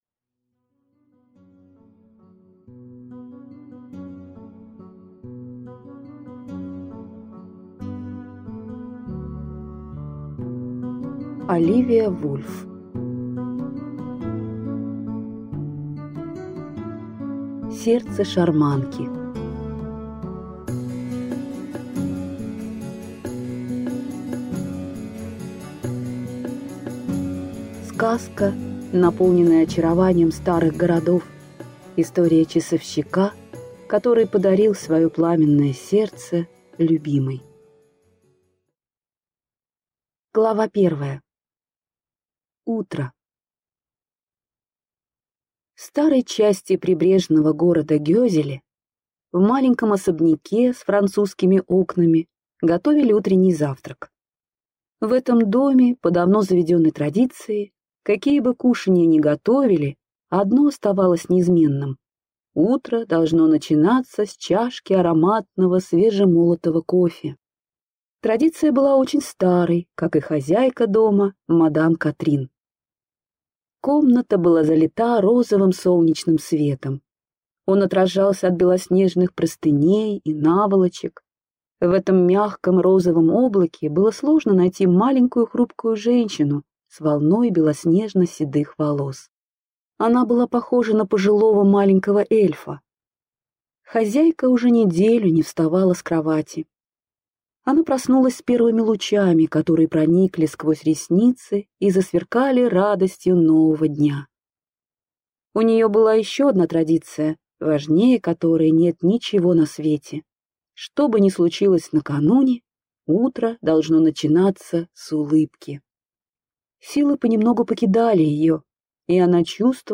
Аудиокнига Сердце шарманки | Библиотека аудиокниг
Прослушать и бесплатно скачать фрагмент аудиокниги